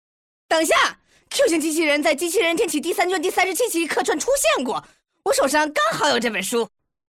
动漫语音
欧文： 15岁左右，尖细的音色，声优通过尖细的音色和极快的语速和夸张自恋的表演方式，演绎出一个沉迷动漫领域，洋洋自得的中二病的倒霉蛋形象。